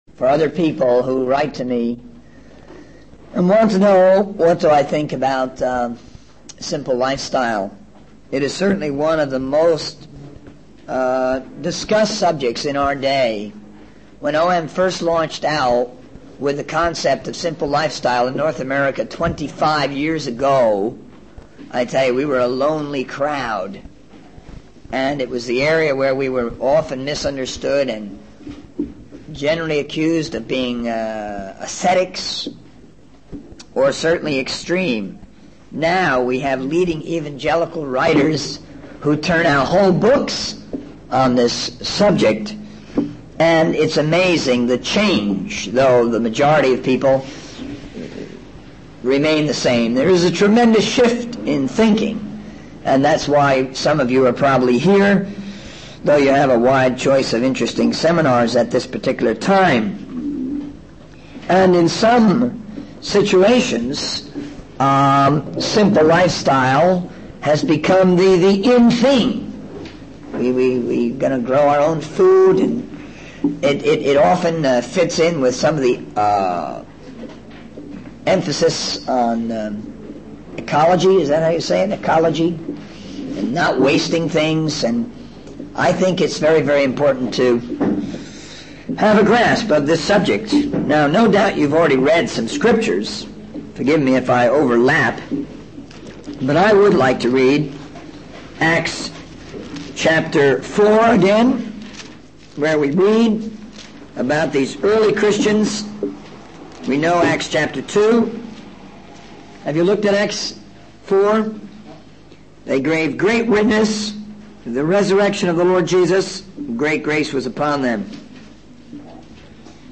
In this sermon, the preacher emphasizes the importance of preaching the word of God and evangelizing the world.